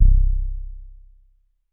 BASS1 C1.wav